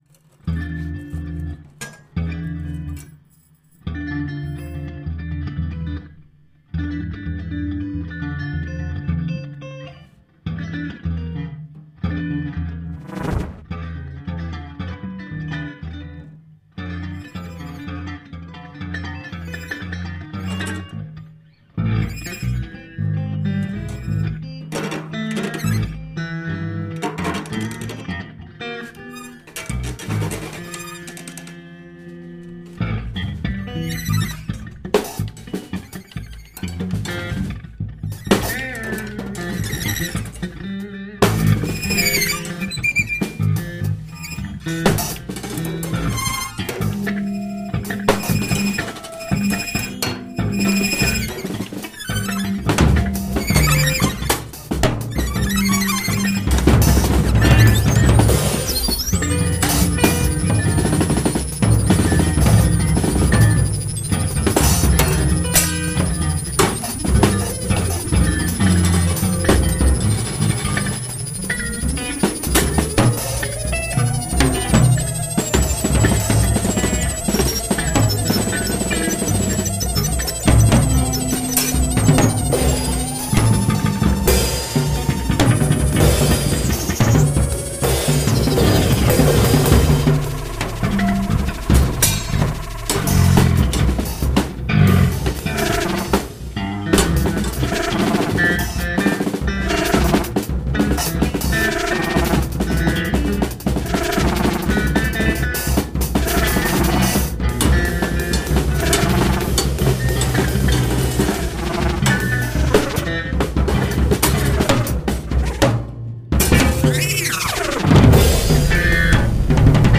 guitar
VCS3 & electronics
drums
Recorded at Confort Moderne, Poitiers, France